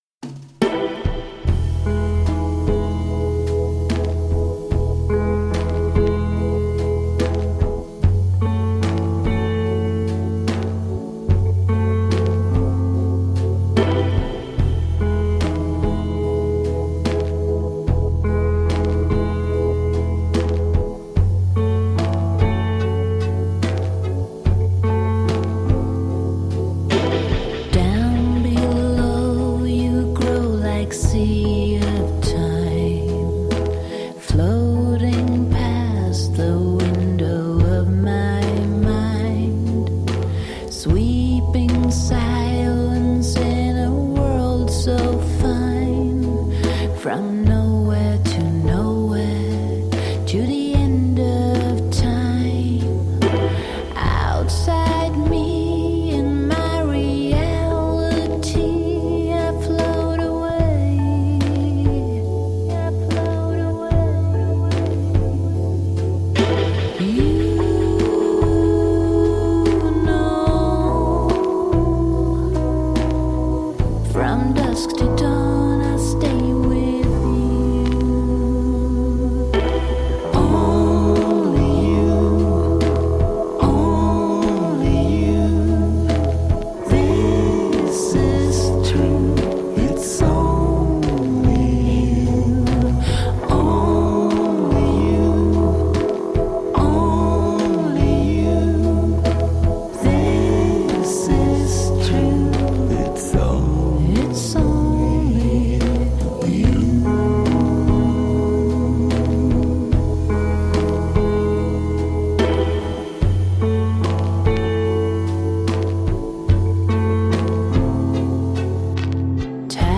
Groovy downtempo rock.
Alt Rock, Other, Downtempo